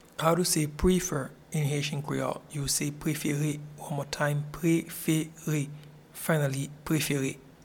Pronunciation and Transcript:
Prefer-in-Haitian-Creole-Prefere.mp3